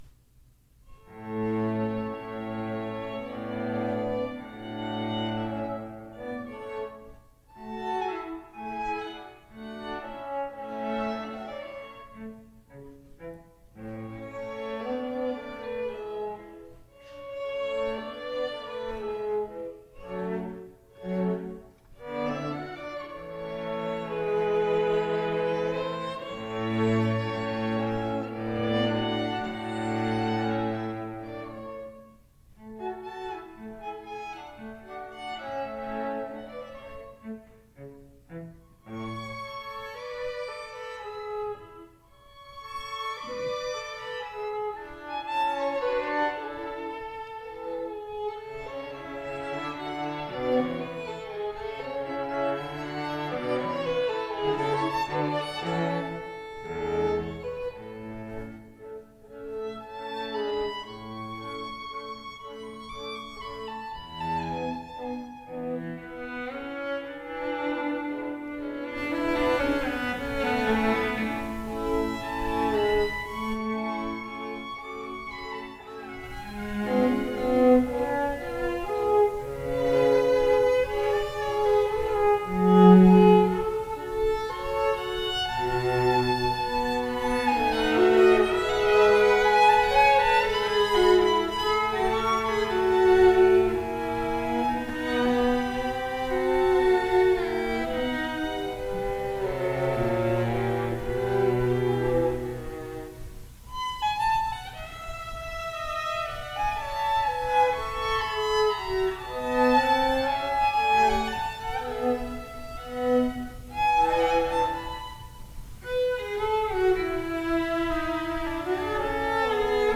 2:00 PM on August 13, 2017, St. Mary Magdalene
Chamber Groups
Allegro